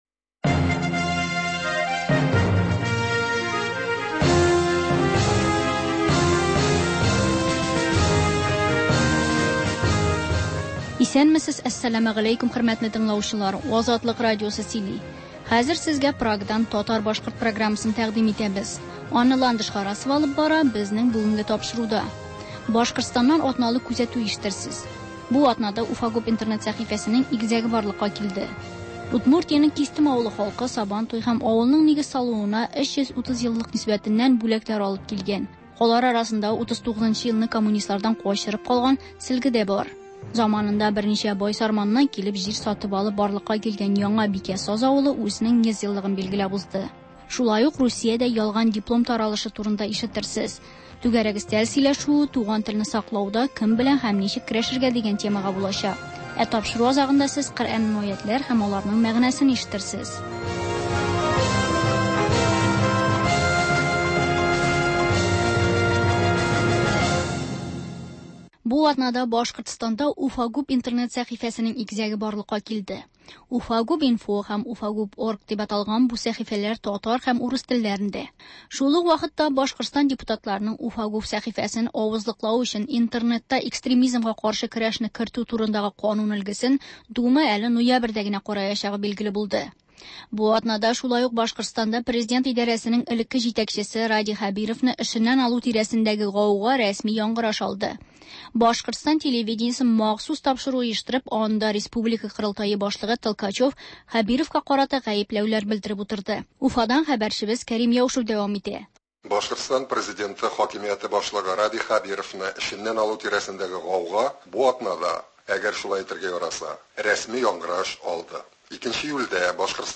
Азатлык радиосы бар атнага күз сала - Башкортстаннан атналык күзәтү - түгәрәк өстәл артында сөйләшү